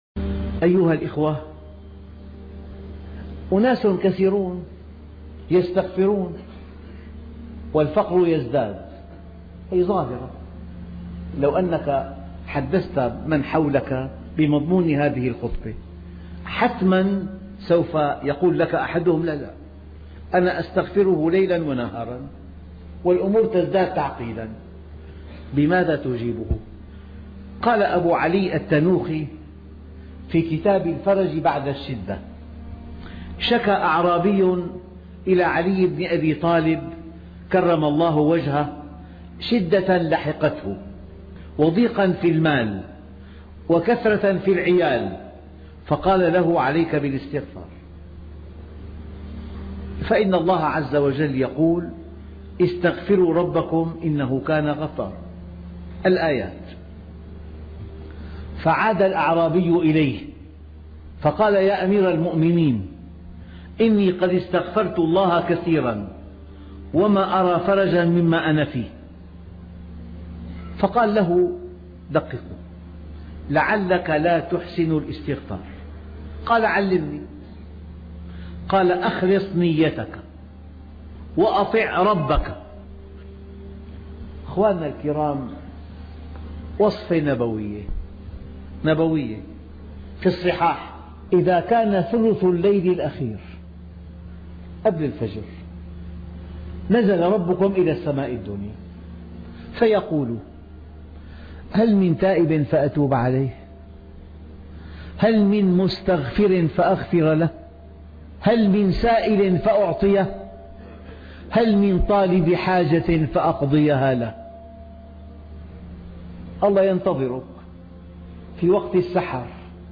الاستغفار سر تغيير الأقدار دروس مؤثرة - الشيخ محمد راتب النابلسي